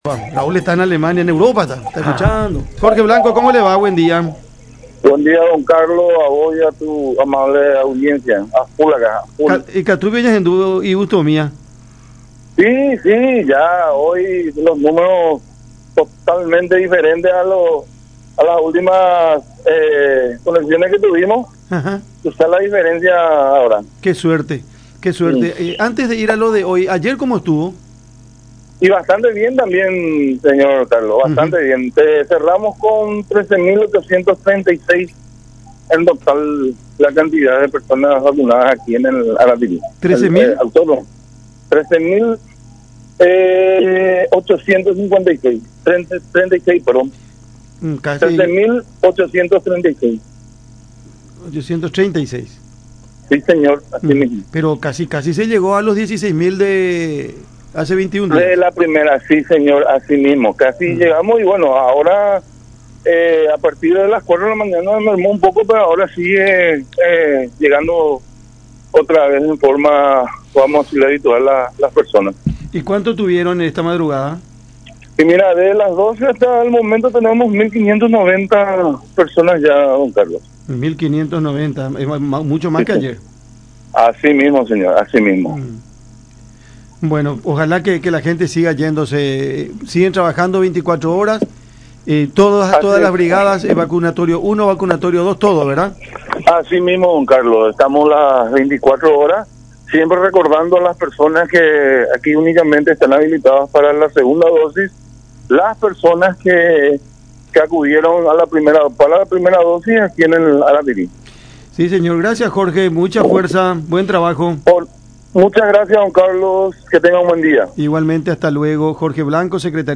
en conversación con Cada Mañana a través de La Unión